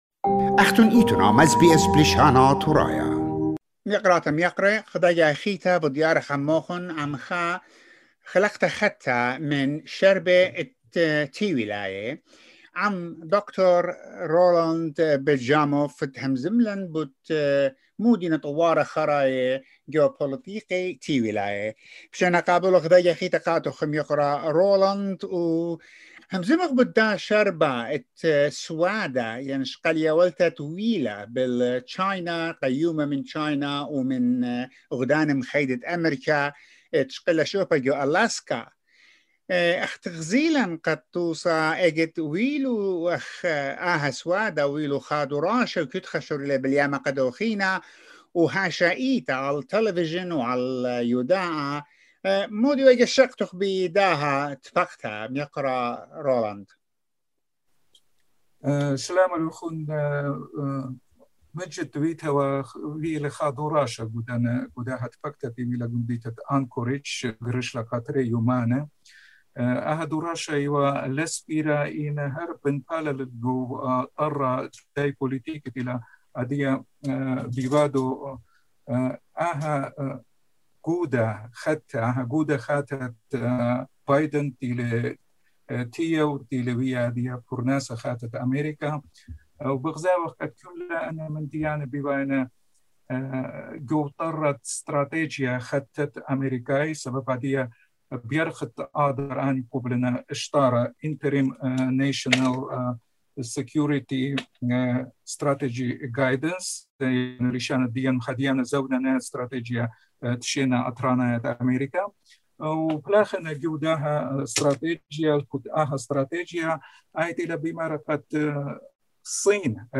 Report about the latest talks between the US and China that took place in Alaska last Friday.